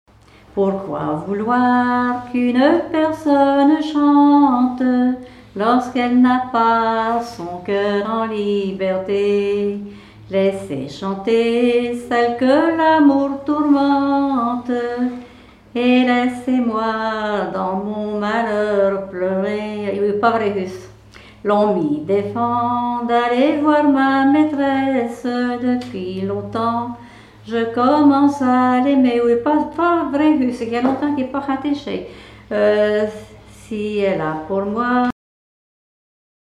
Genre strophique
Veillées de chanteurs traditionnels
Pièce musicale inédite